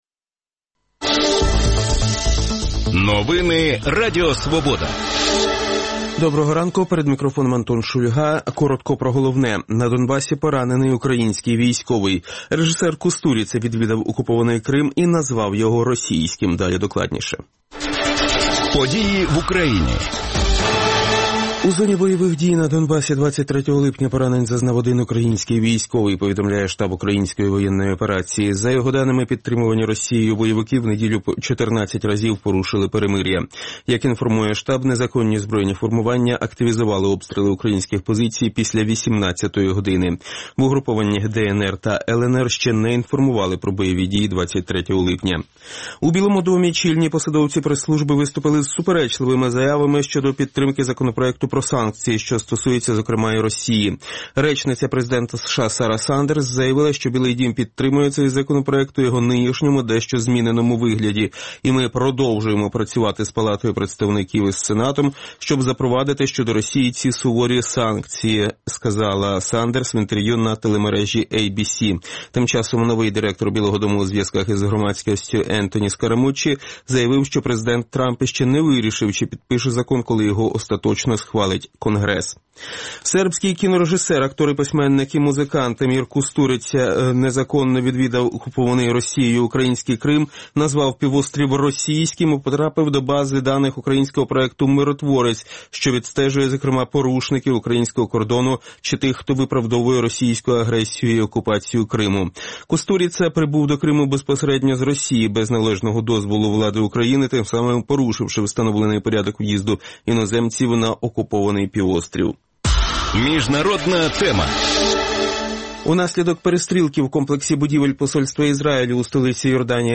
говоритиме з гостями студії